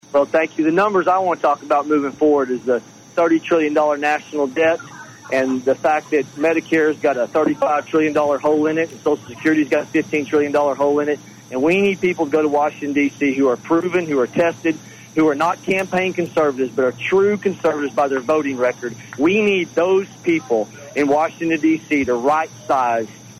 Brecheen called in as well